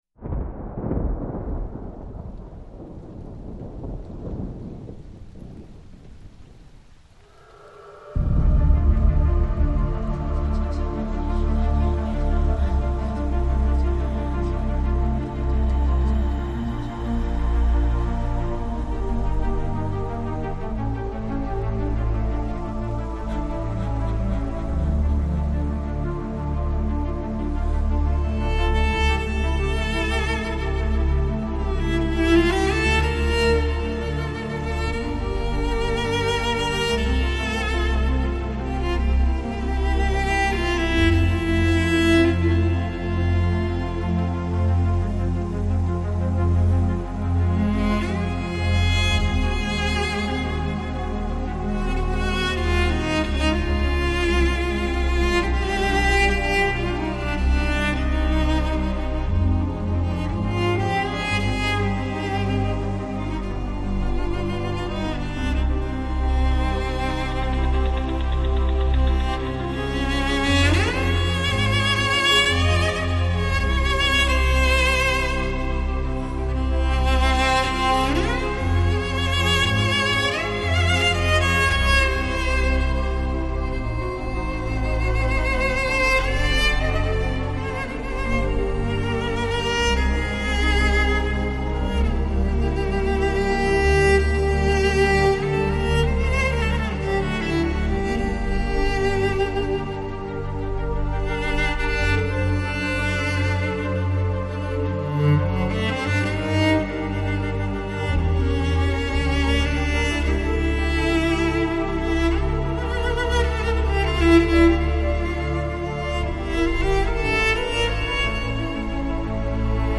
New Age, Instrumental, Classical Лейбл